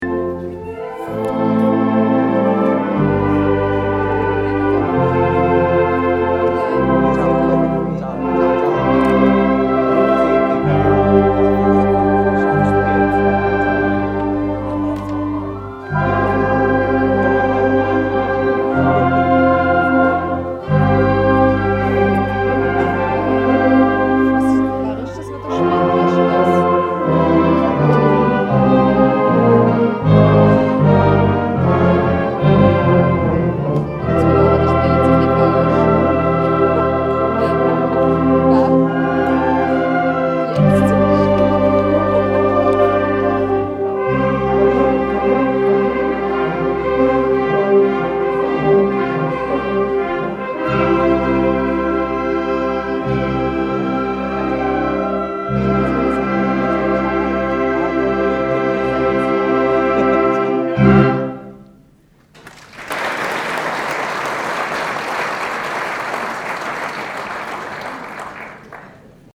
Exklusiv für die infamy-Hörerschaft gibt es hier das Ständchen, mit dem Bundesrätin Widmer-Schlumpf gestern in Münchenstein empfangen wurde.